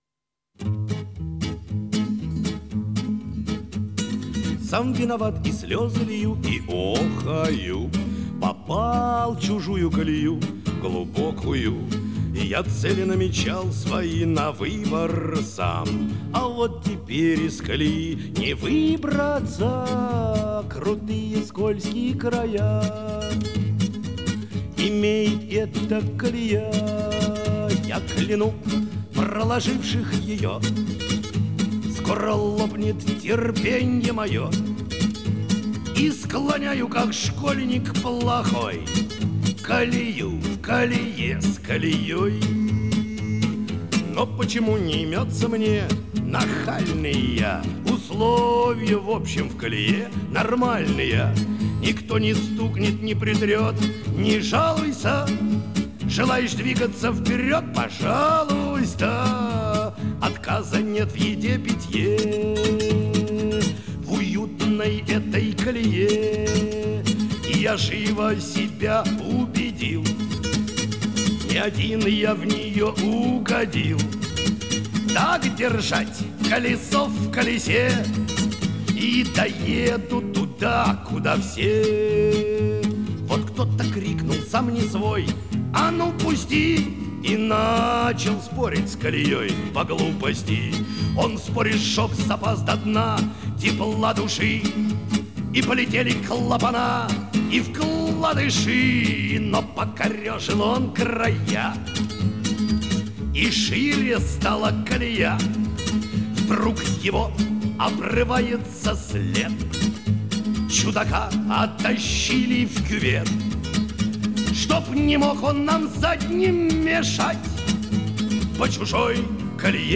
русская песня